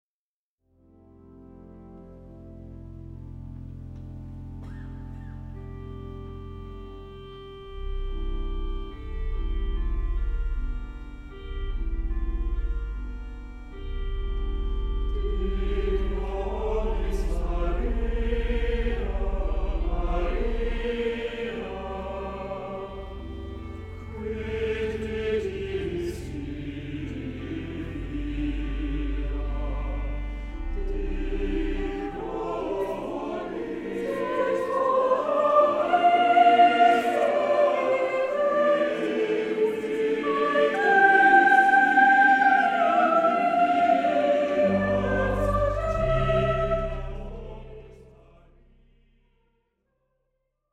Recording venue: York Minster